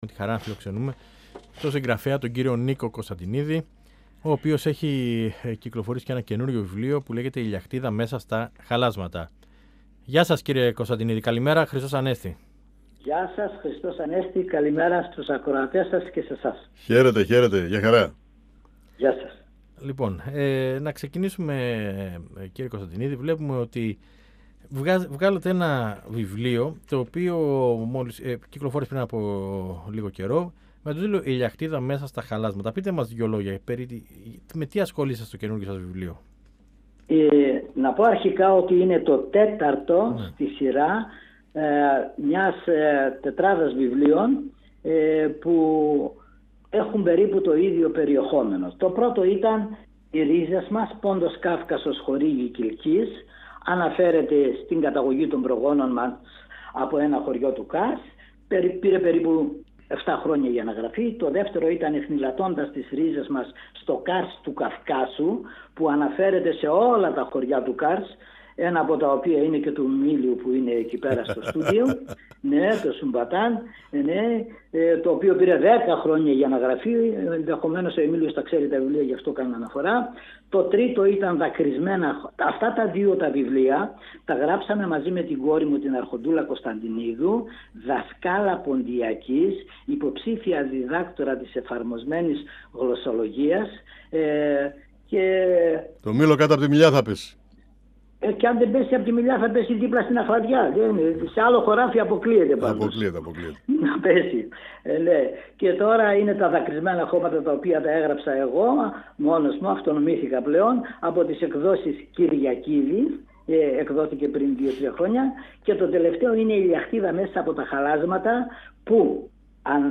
Ενημερωση Συνεντεύξεις ΕΡΤ3